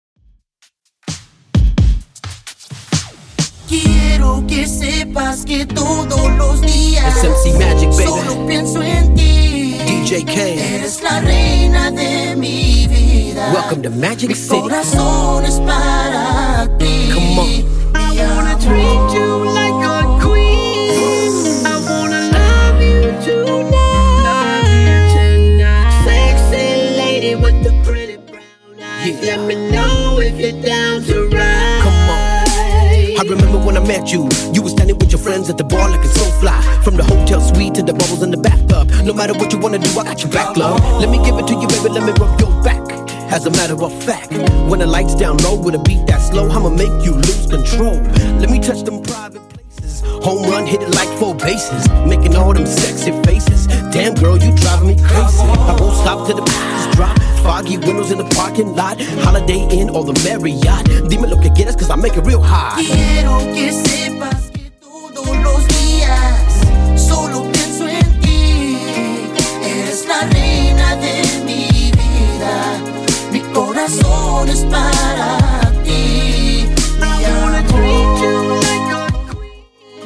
Genre: 60's
BPM: 120